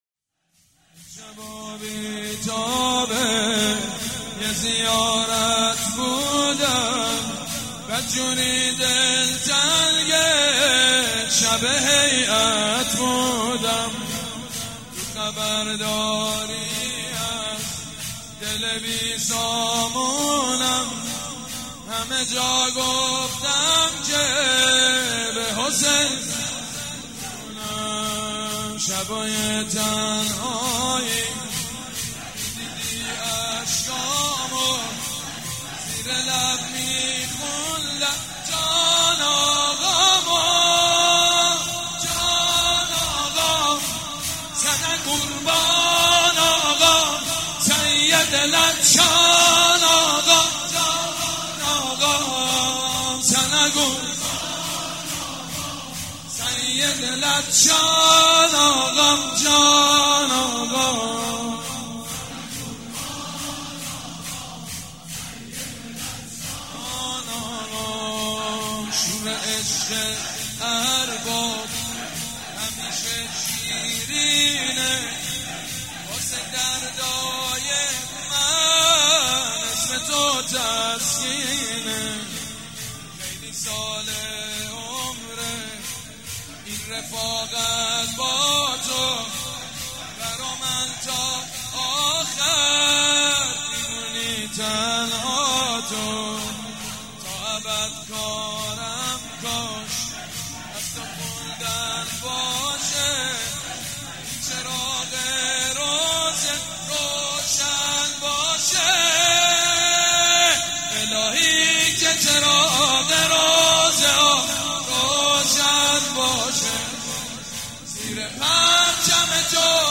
شب هفتم رمضان95
زمینه، روضه، مناجات